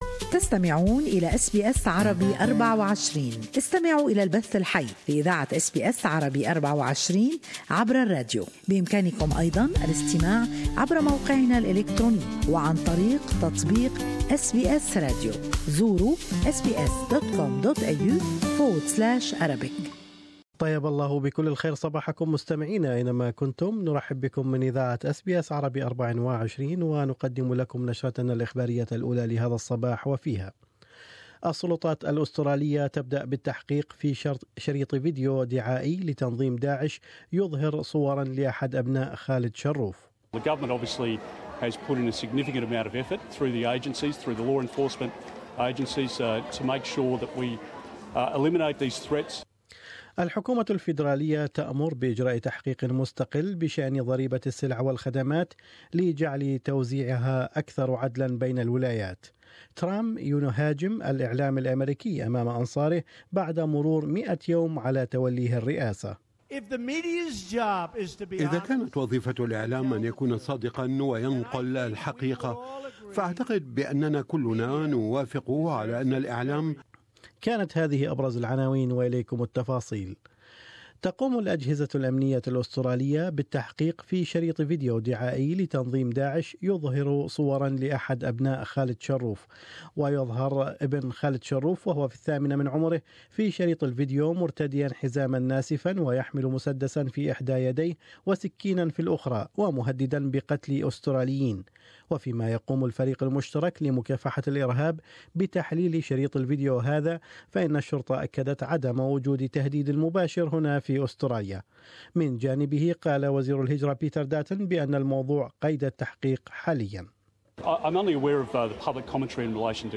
Australian and world nes in the Morning News Bulletin.